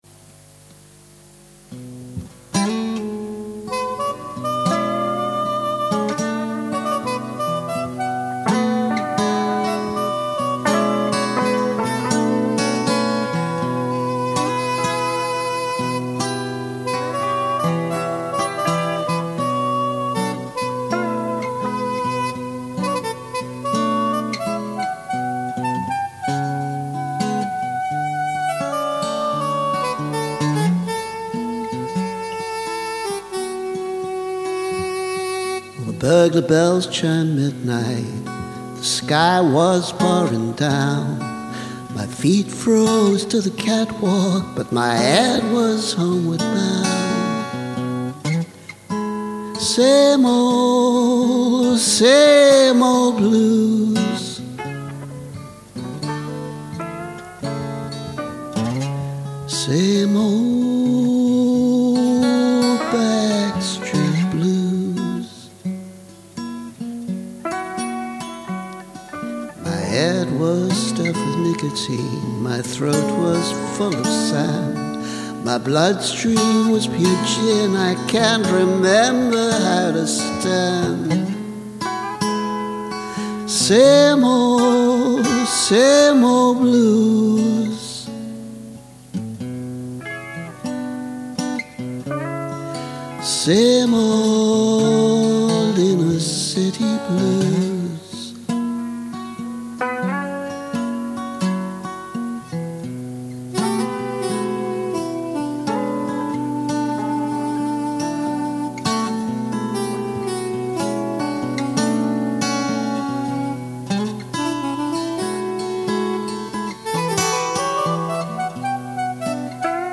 vocal, guitars, keyboards
(Remastered version)